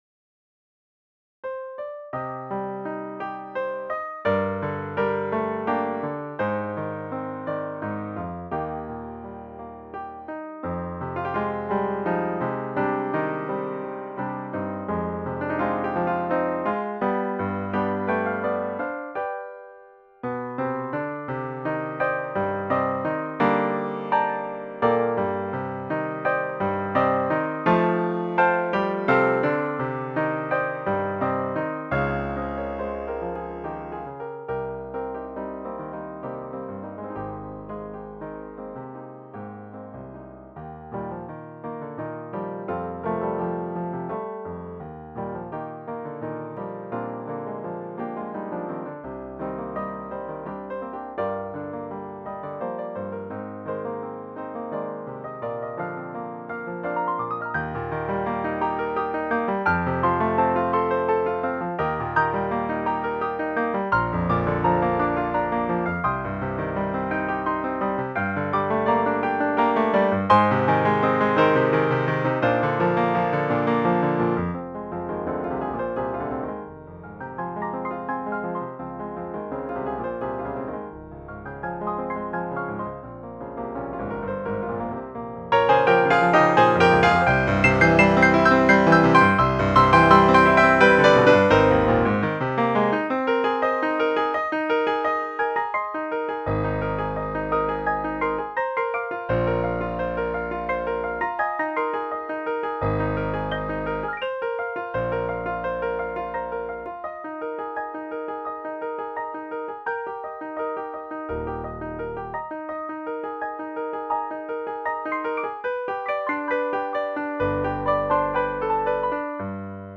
suite para piano solo